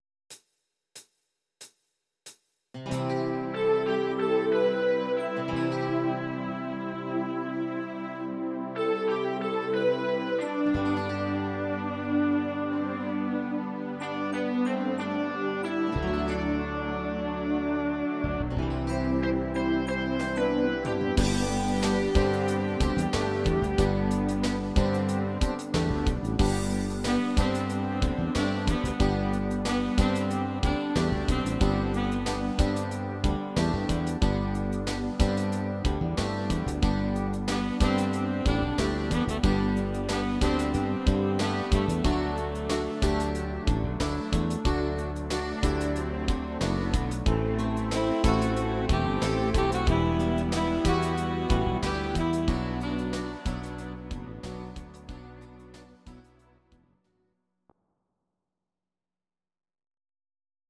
Audio Recordings based on Midi-files
German, Duets, 1990s